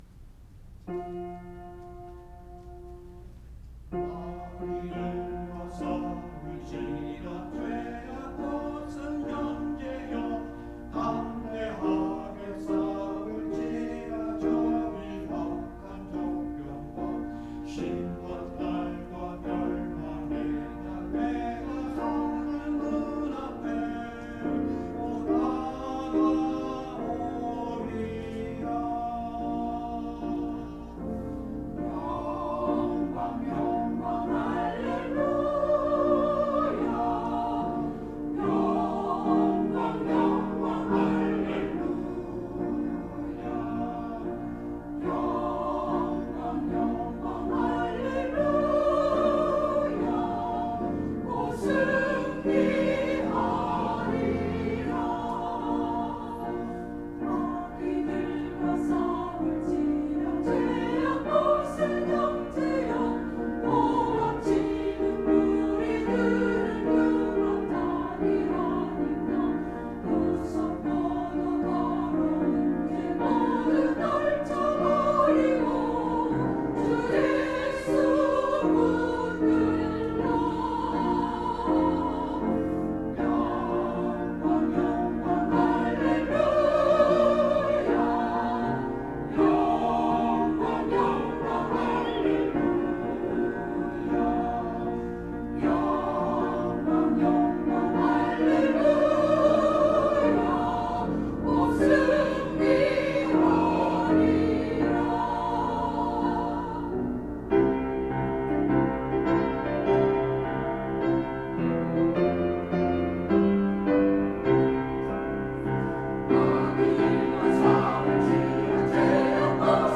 찬양대
[주일 찬양] 마귀들과 싸울지라